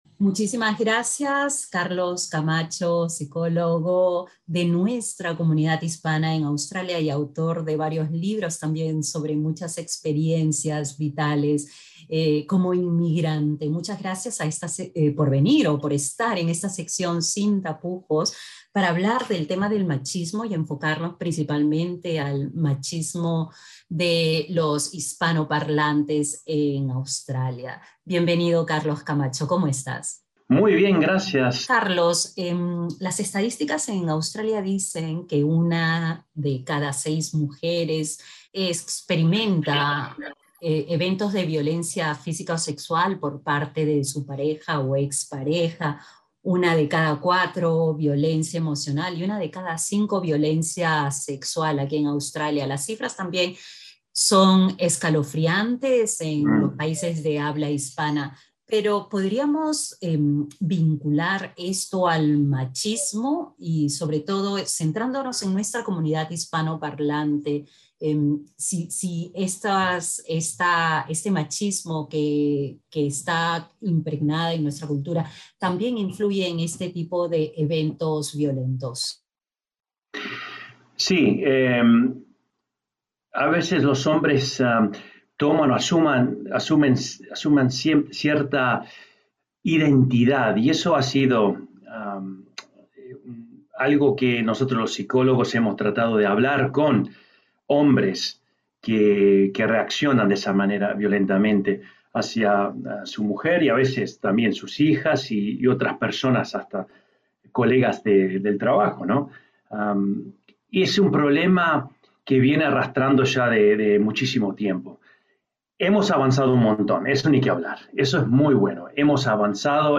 dialoga con SBS Spanish sobre las estrategias para adaptarse y sobre todo evitar la violencia.